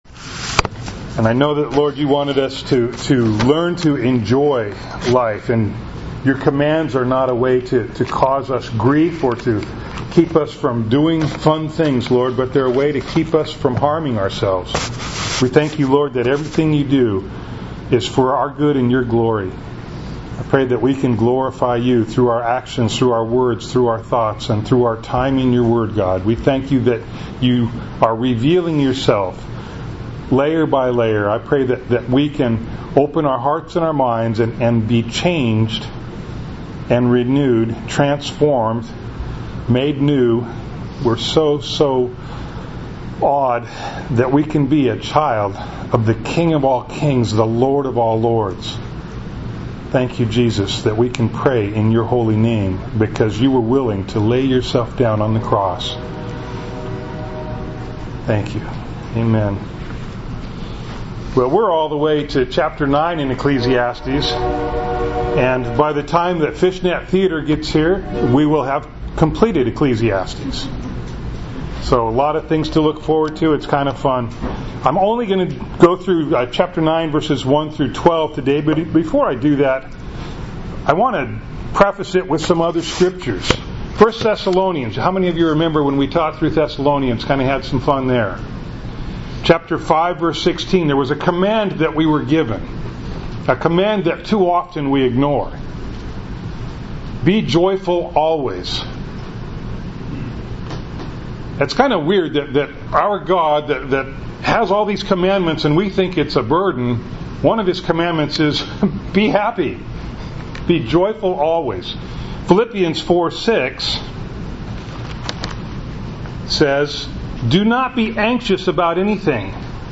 Passage: Ecclesiastes 9:1-12 Service Type: Sunday Morning